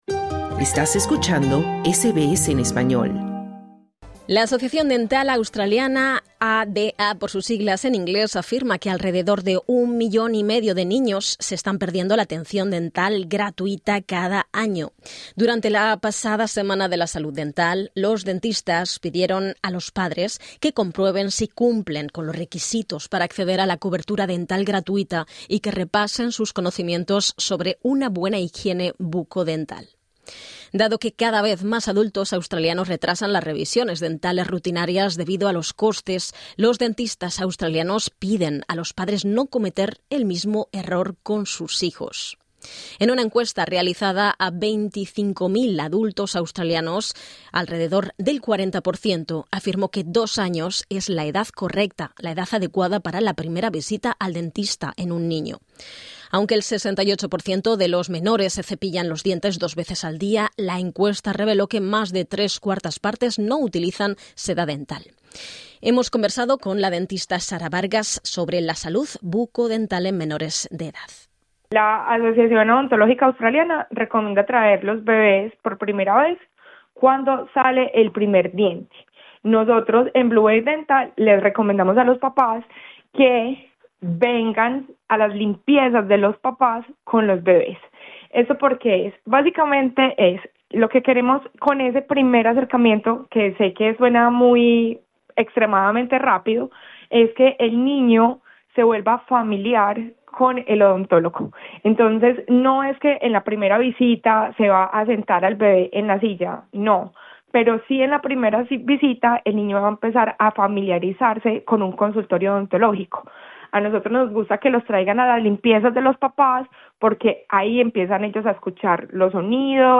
SBS conversó con la odontóloga